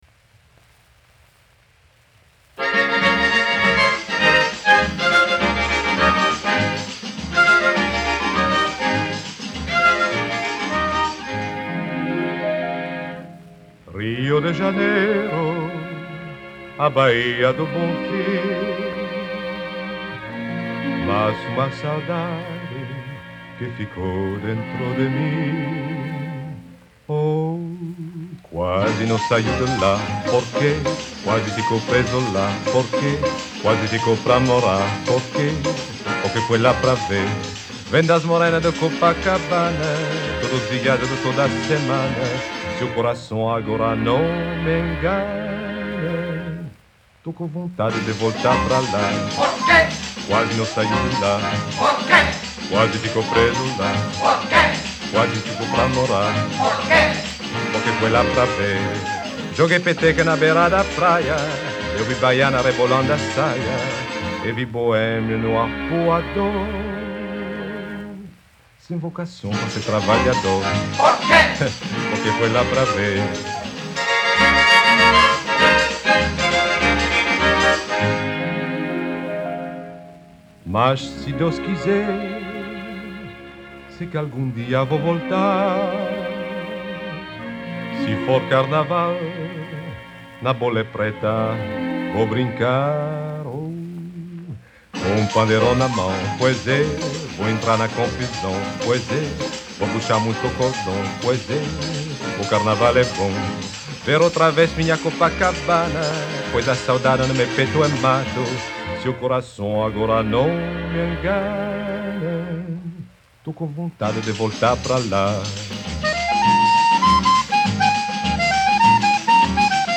очень романтический стиль.